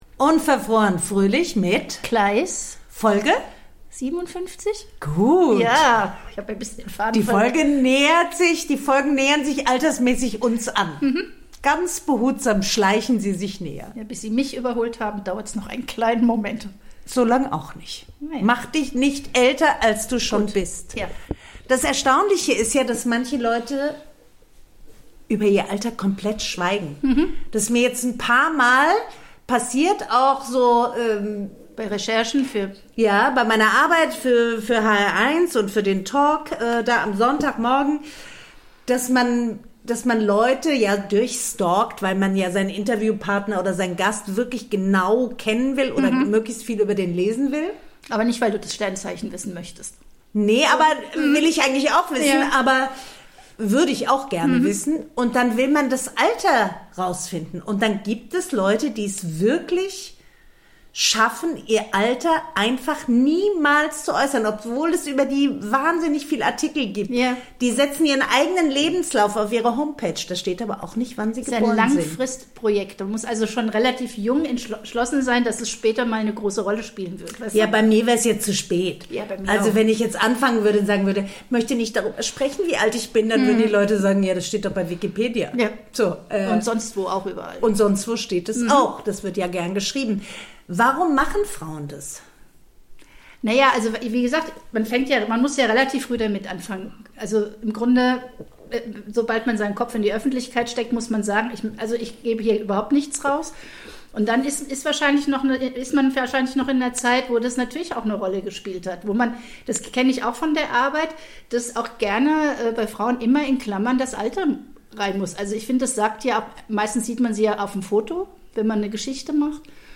die beiden Podcasterinnen